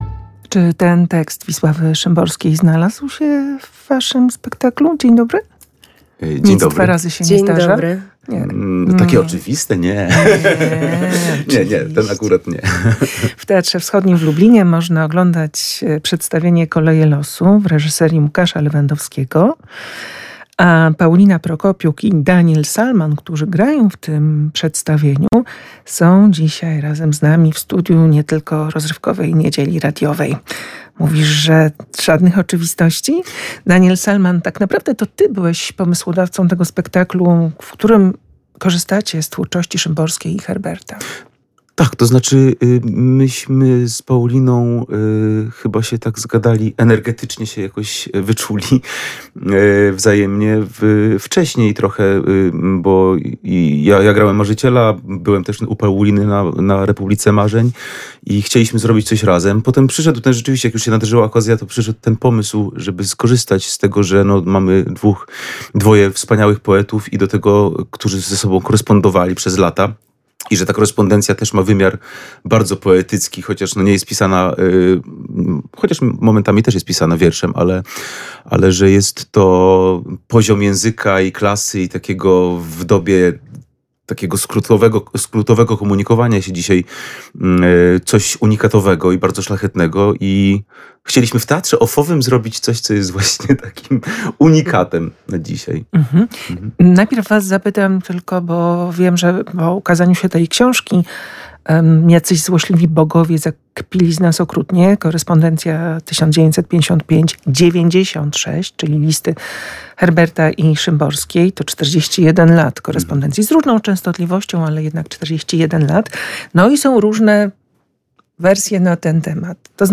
Spotkanie w Teatrze Starym